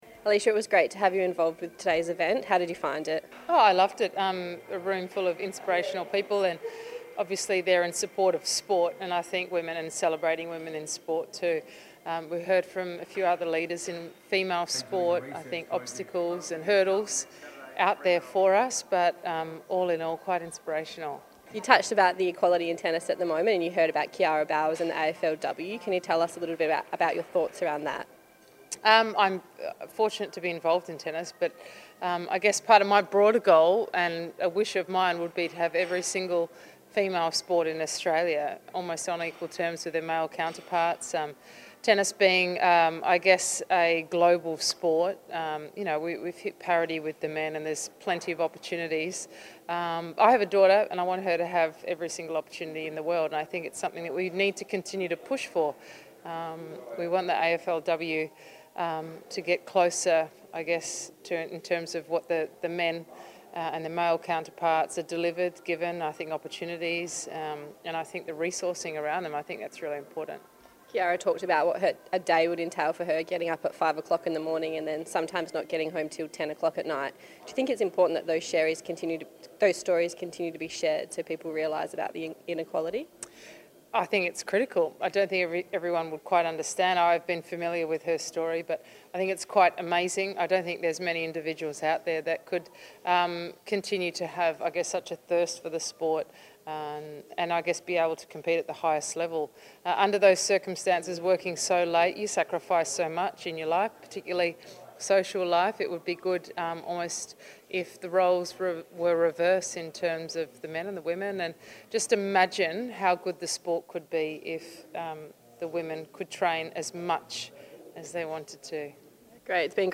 Alicia Molik was a special guest at Fremantle's International Women's Day Luncheon.